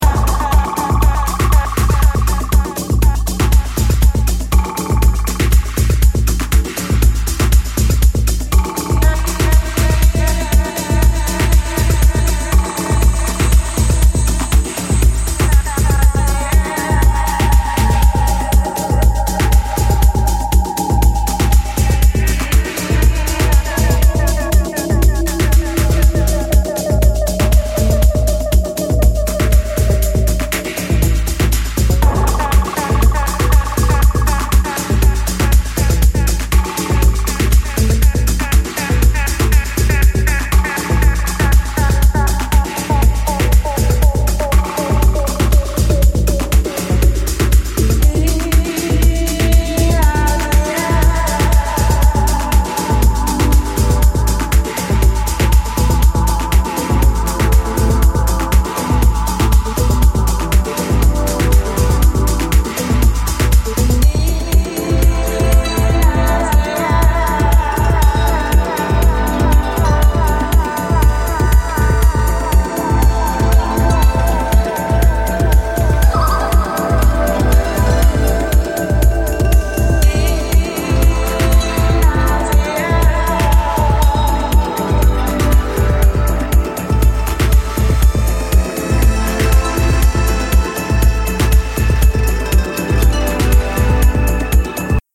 パーカッションとFXのダークな応酬で迫ります